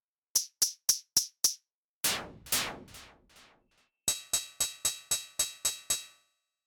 Cowbell? :crazy_face:
:stuck_out_tongue: stuff like that … especially the last sound, like an old printer. love it :stuck_out_tongue: